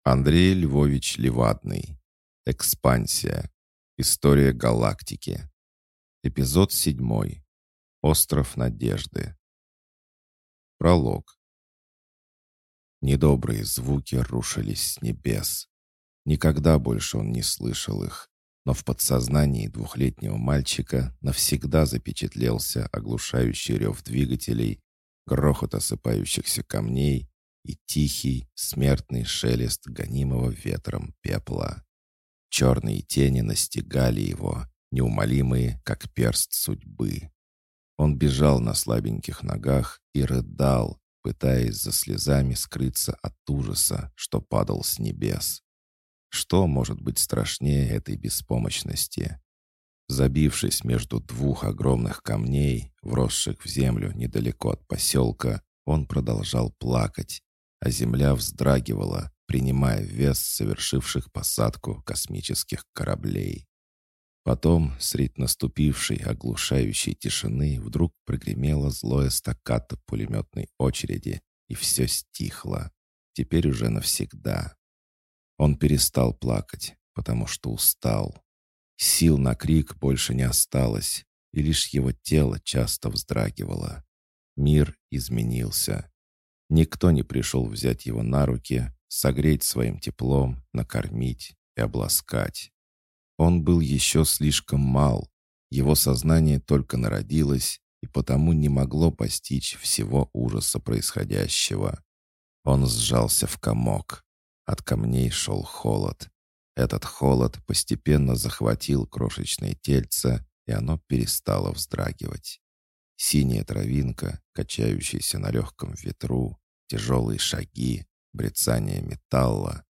Аудиокнига Остров Надежды | Библиотека аудиокниг
Прослушать и бесплатно скачать фрагмент аудиокниги